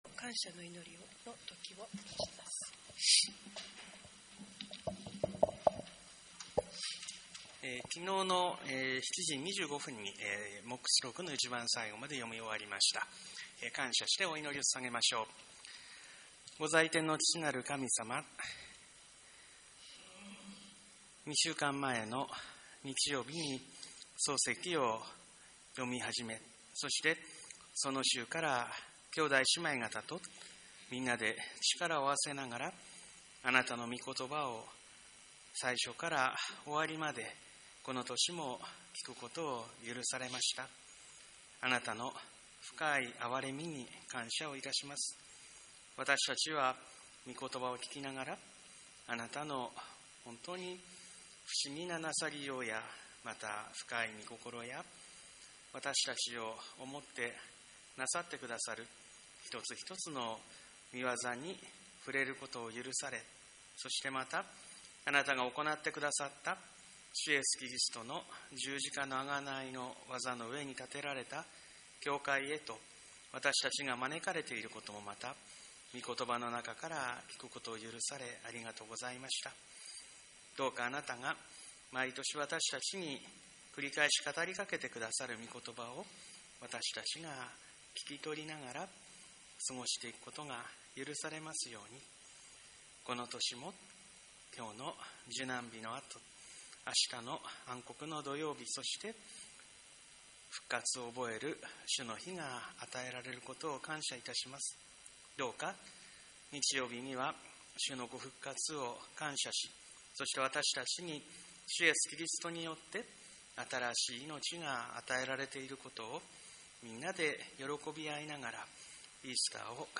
4月18日受難日礼拝後、通読終了感謝の祈り
2025聖書全巻リレー通読終わりの祈り音声をもって終了